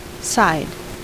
Ääntäminen
Ääntäminen US Tuntematon aksentti: IPA : /saɪd/ Haettu sana löytyi näillä lähdekielillä: englanti Käännöksiä ei löytynyt valitulle kohdekielelle.